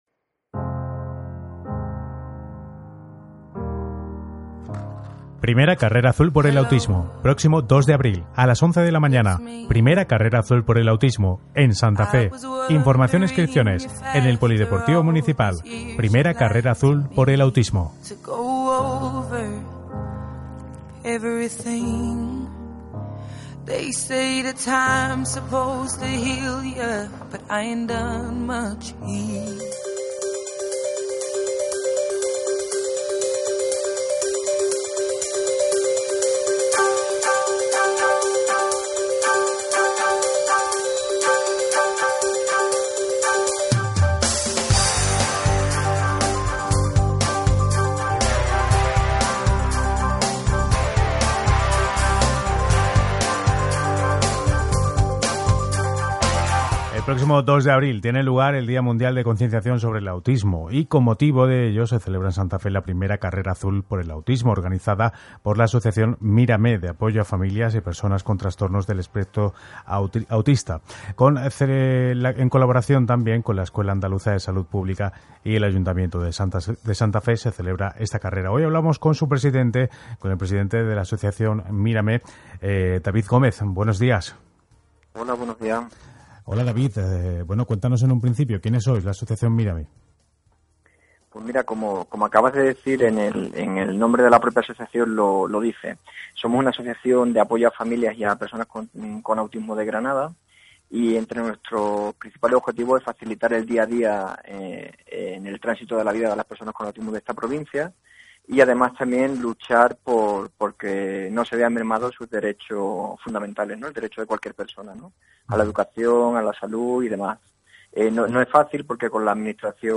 Entrevistas en radio por la I Carrera Azul por el Autismo | Escuela Andaluza de Salud Pública